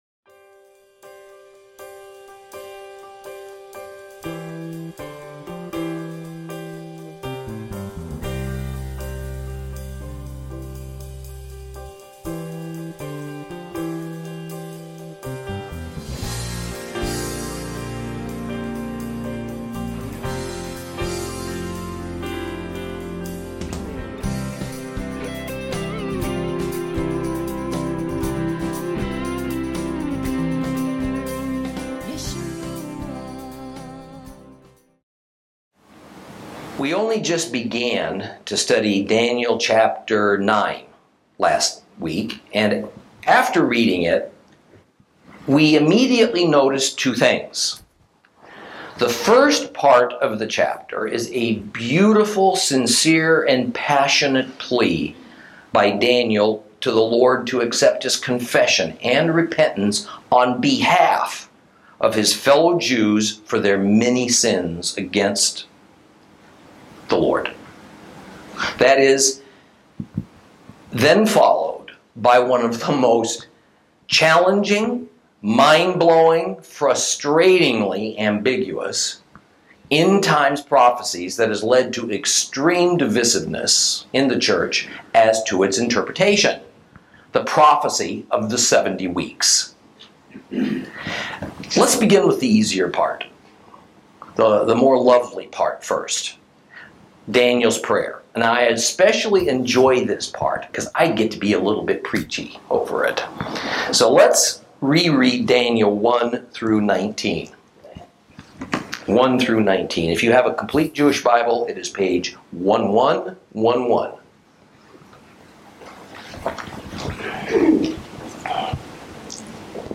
Lesson 25 Ch9 - Torah Class